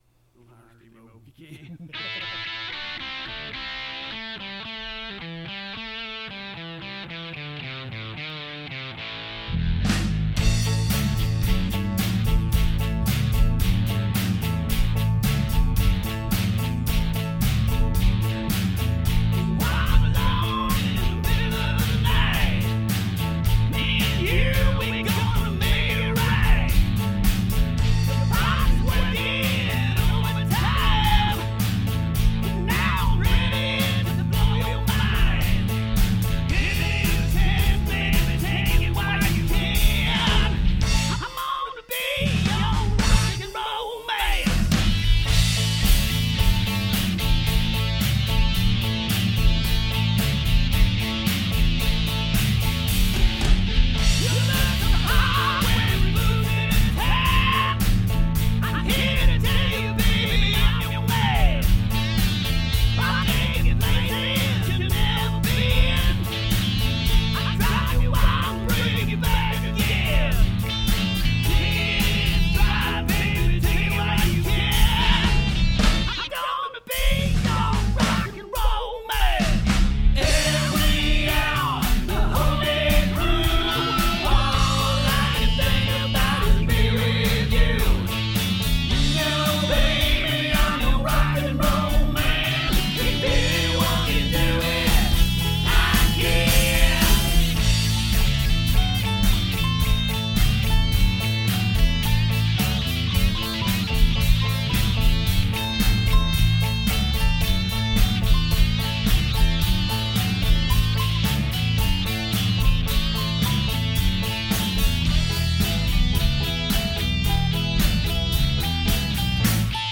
this is the rough mix of our latest song.